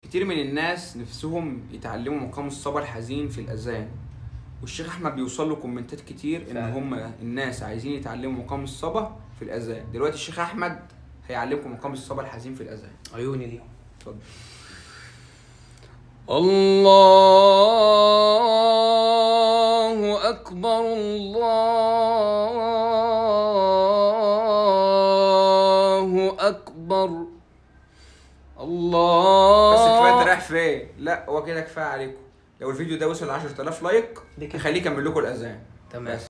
تعليم مقام الصبا الحزين في Sound Effects Free Download